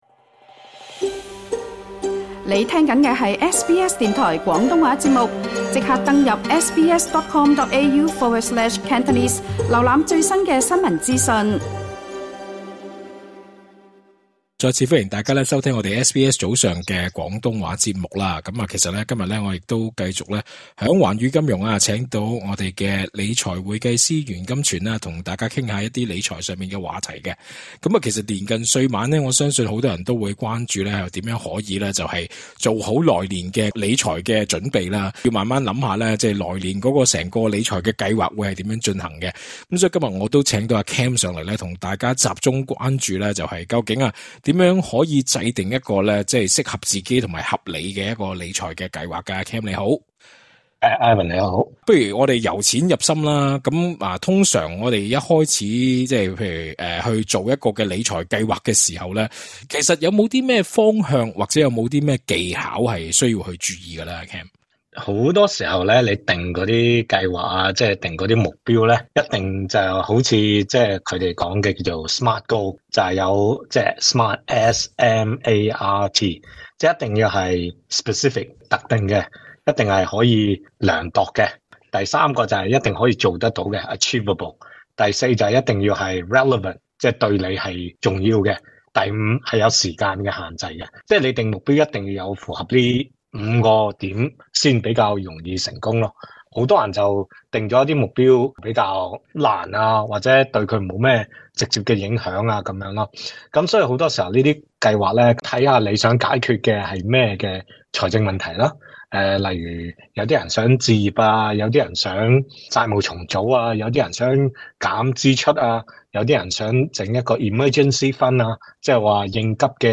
SBS中文廣東話節目請來理財會計師教大家如何制定有效「新年理財規劃」，亦會詳細分析新一年（2024年）的投資環境及大方向是否已有轉變?